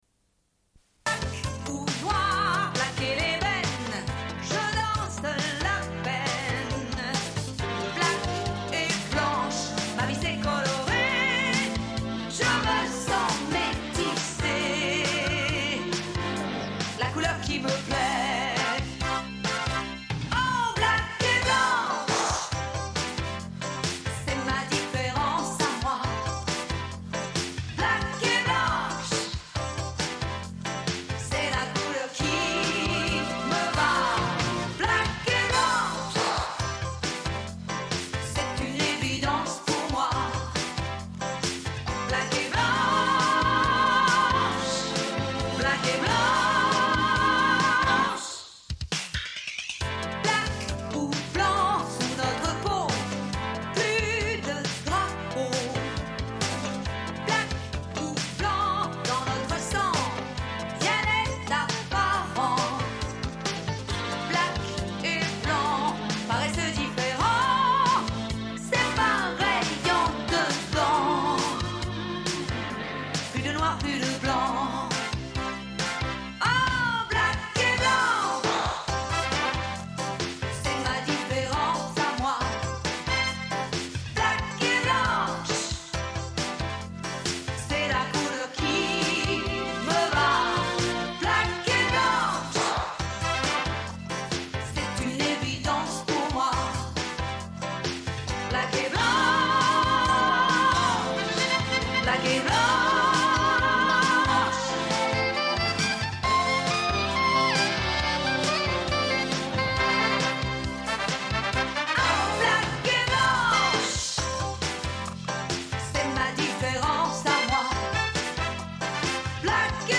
Une cassette audio, face A31:11